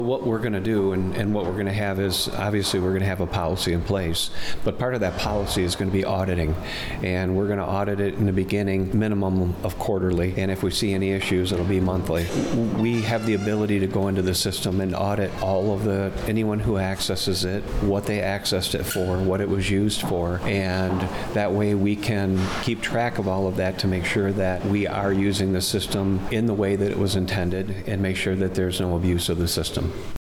That was Lenawee County Sheriff Troy Bevier.